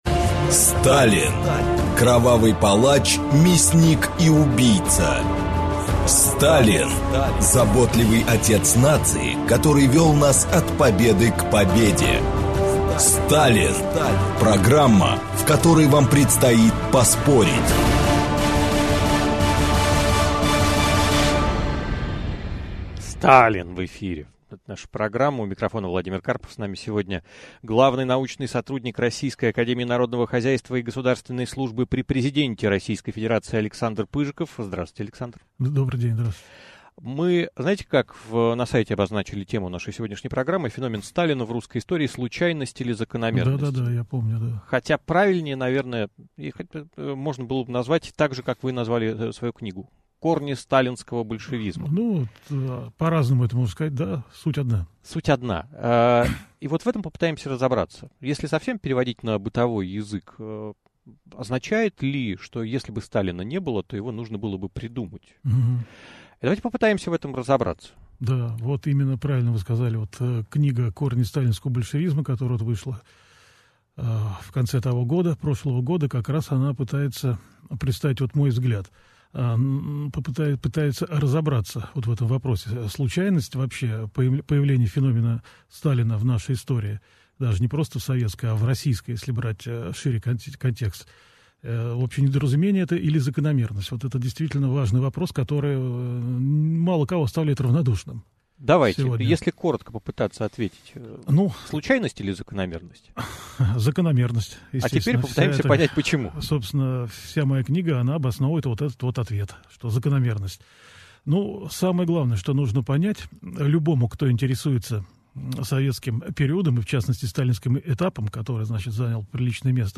Aудиокнига Феномен Сталина в русской истории: случайность или закономерность?